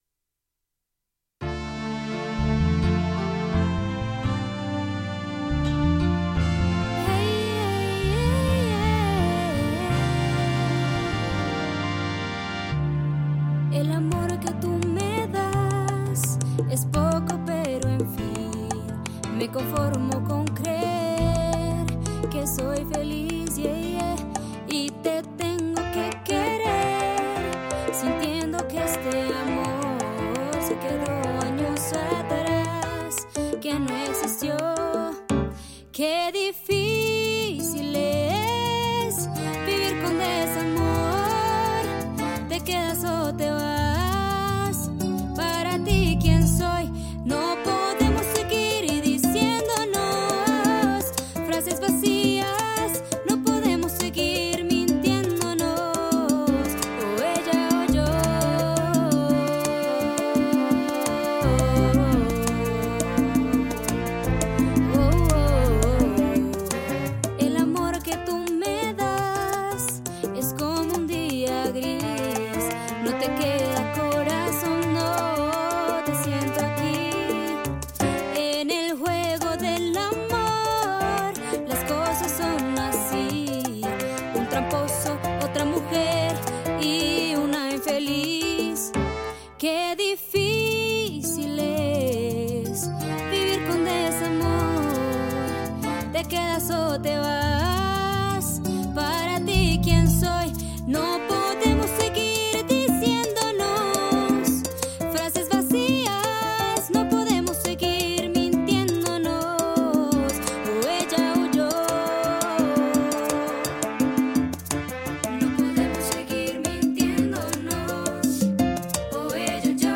Melismas, Salsa, R&B, Arreglo musical